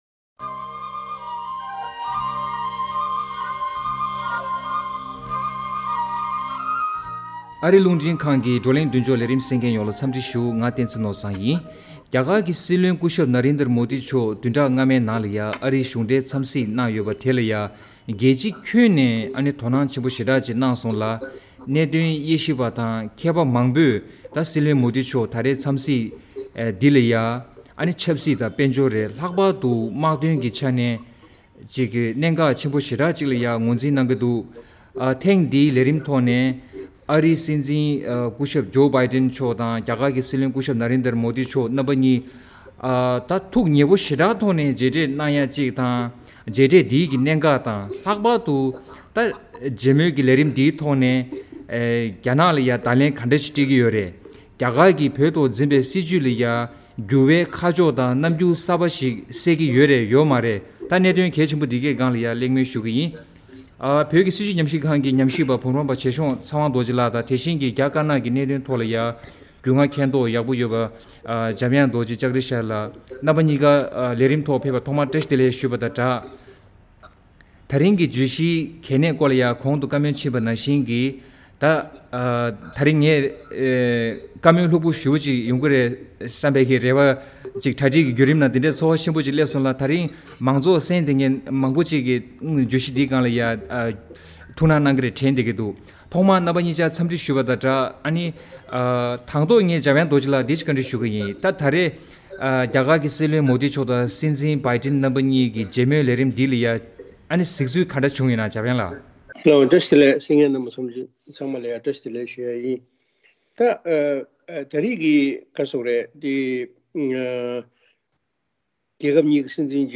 བགྲོ་གླེང་མདུན་ཅོག མོ་ཌི་དང་བྷ་ཌན་གཉིས་ཀྱི་མཇལ་མོལ་གྱིས་རྒྱ་ནག་ལ་བརྡ་ལན་དང་བོད་དོན་འབྲེལ་ཆགས།